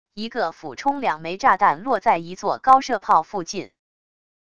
一个俯冲两枚炸弹落在一座高射炮附近wav音频